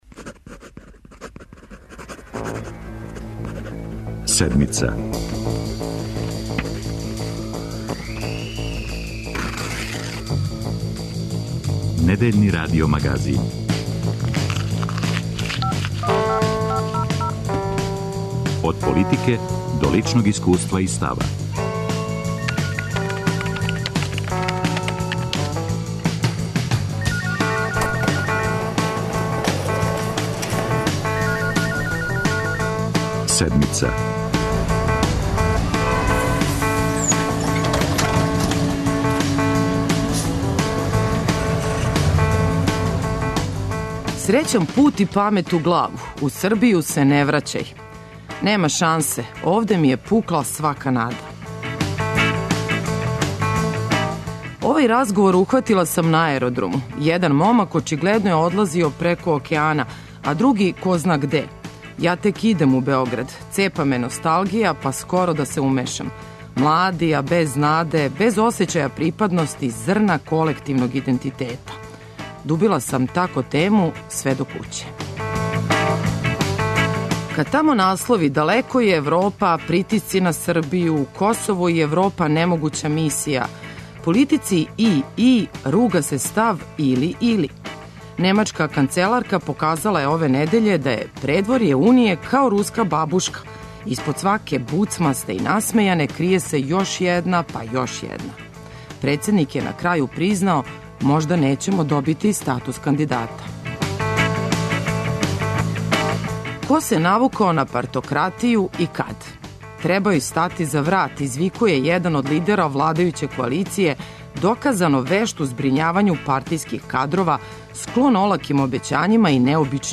Ове недеље подсећамо вас на репортерске записе наших репортера из Новог Пазара, Лесковца, Горњег Милановца.